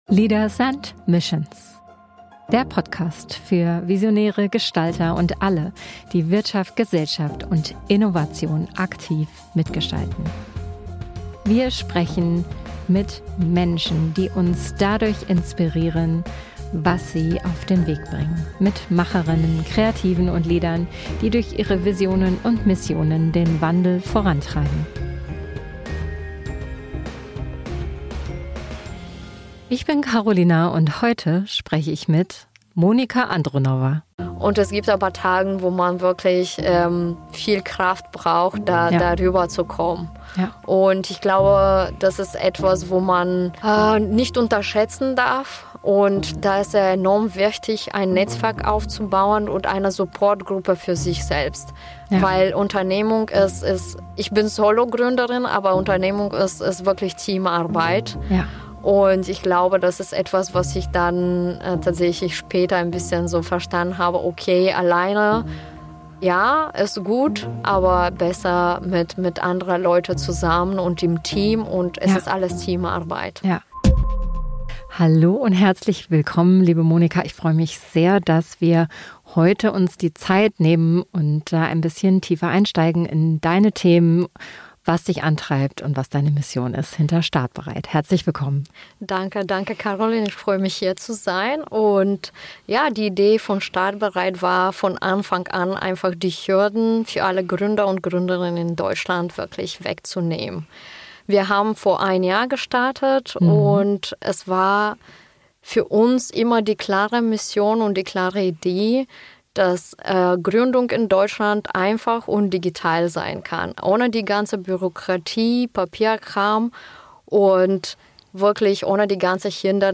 Ein Gespräch über Mut, Klarheit und digitale Hebel, die echten Impact haben – vor allem für internationale Gründer:innen.